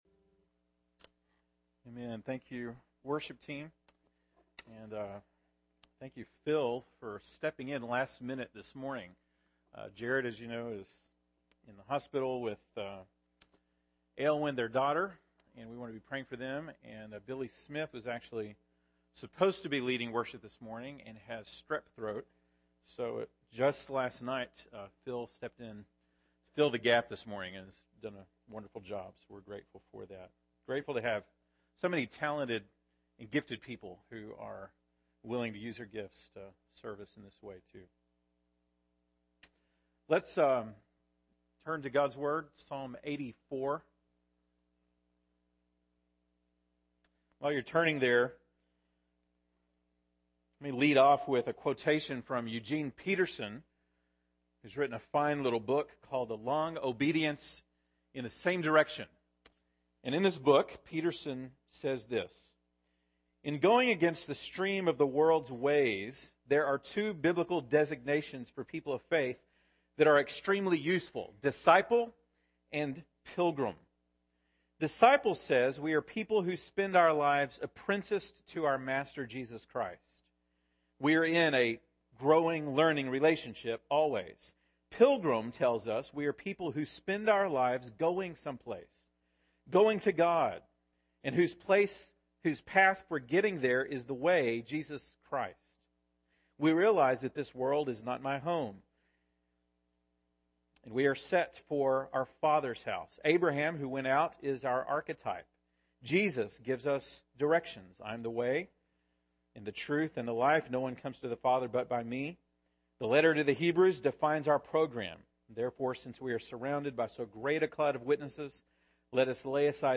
April 26, 2015 (Sunday Morning)